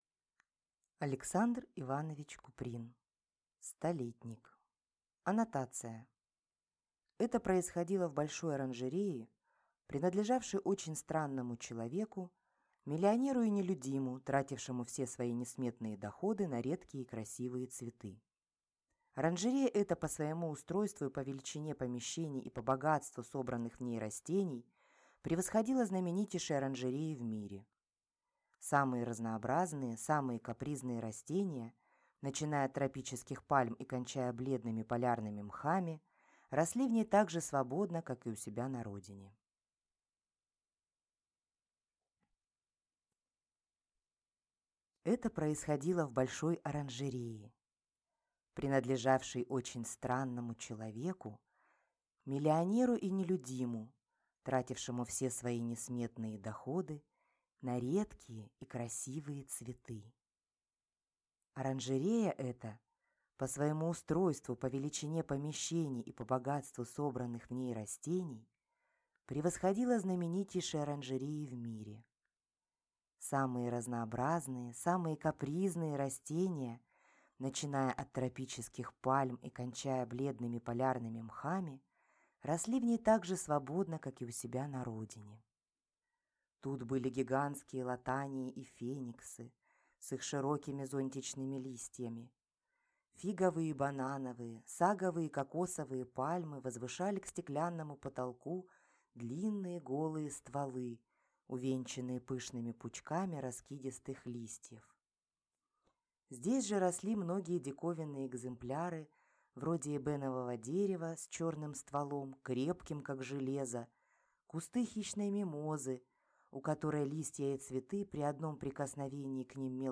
Аудиокнига Столетник | Библиотека аудиокниг